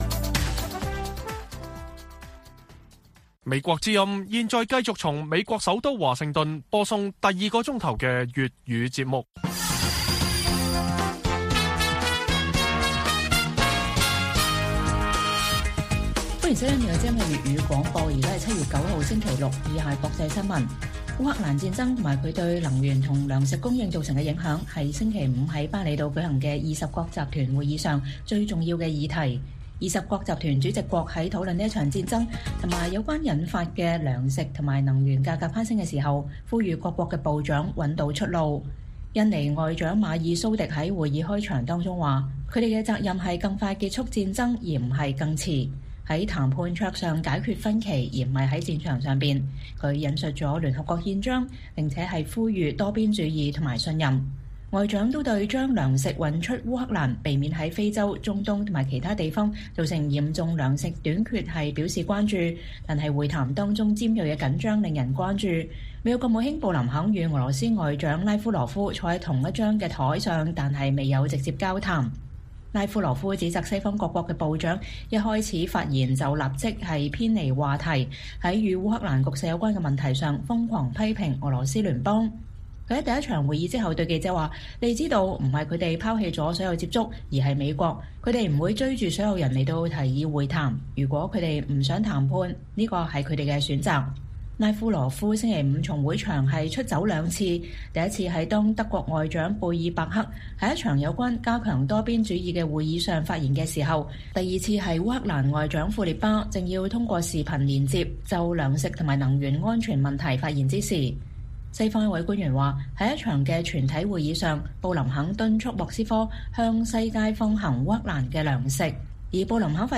粵語新聞 晚上10-11點: G20集團峰會上，各國外長呼籲“找到出路”解決烏克蘭和糧食危機